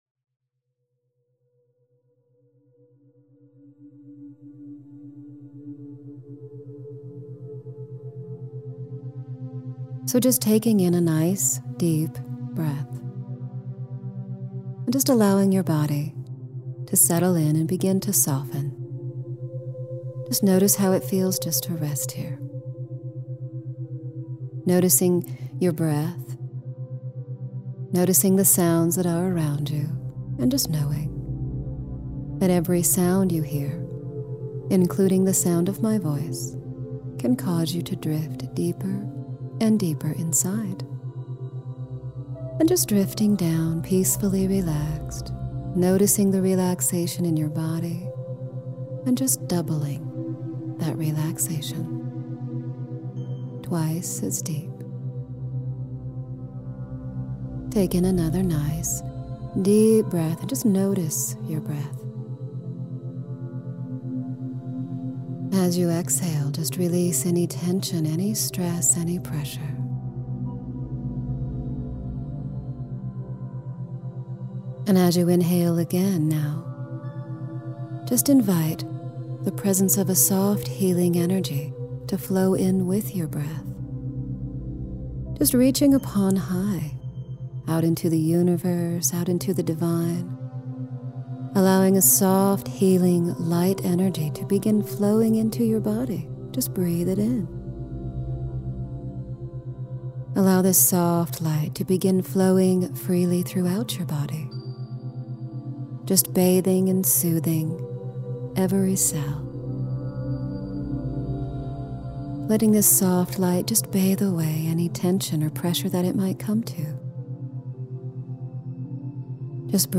Guided Meditation with hypnosis to open your spirit to allow and receive prosperity and abundance. money, security, safety, freedom, easy, flow. Breathe abundance into your cells.